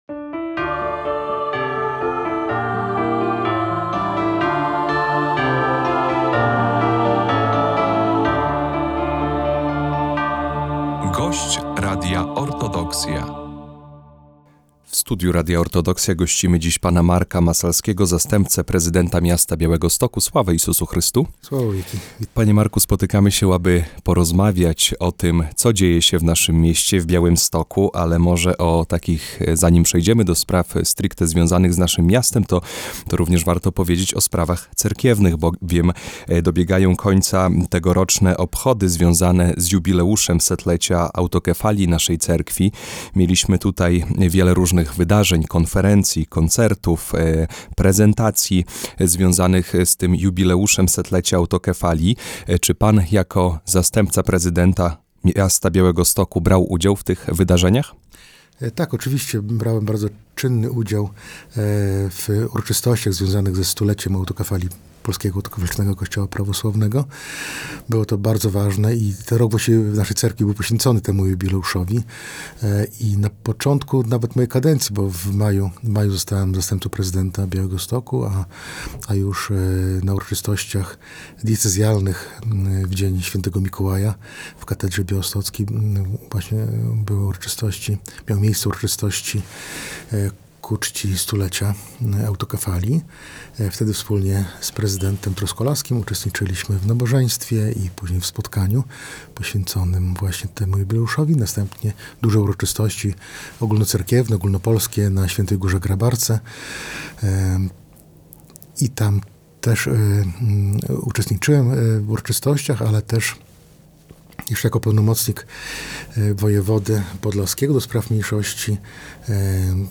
Radio Orthodoxia - Rozmowa z zastępcą Prezydenta Miasta Białegostoku Markiem Masalskim
Zapraszamy do wysłuchania rozmowy z p. Markiem Masalskim zastępcą Prezydenta Miasta Białegostoku.